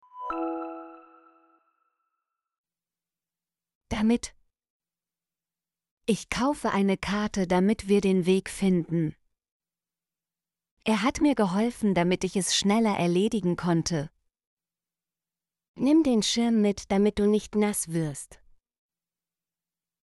damit - Example Sentences & Pronunciation, German Frequency List